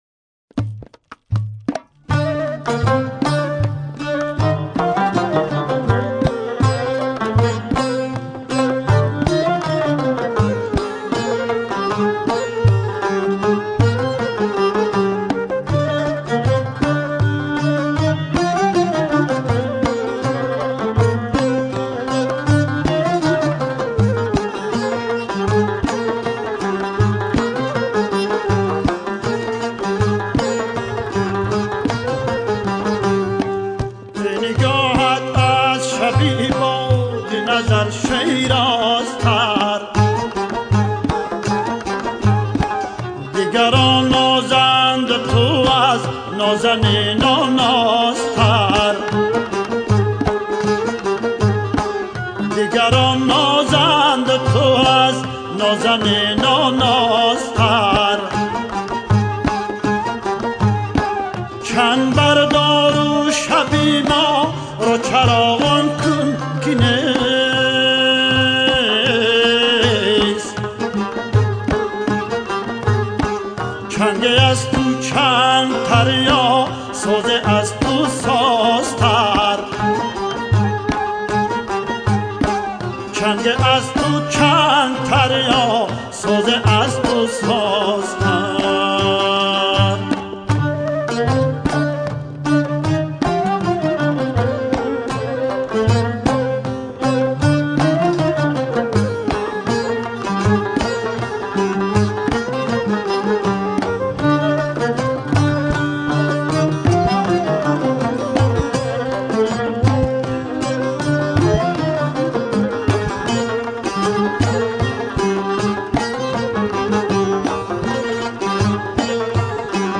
خواننده سرشناس موسیقی تاجیکستان